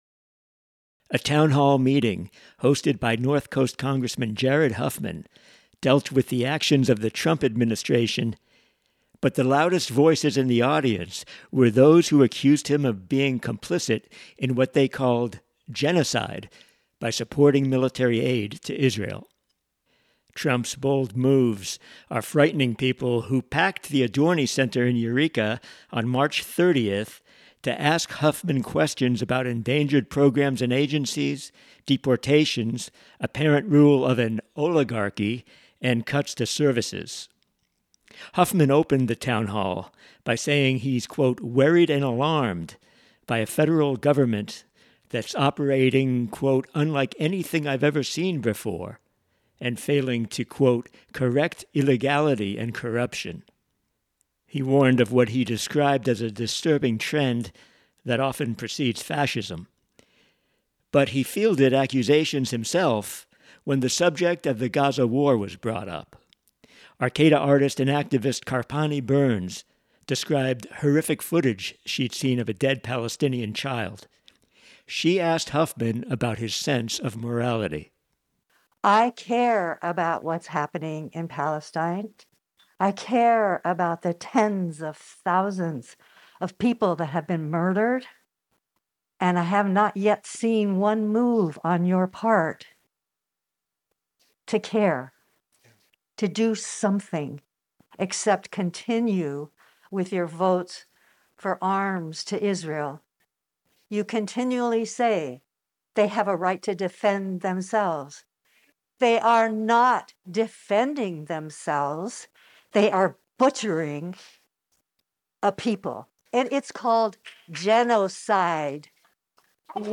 Congressman Jared Huffman's support of military aid to Israel led to some intense criticism during a town hall event in Eureka.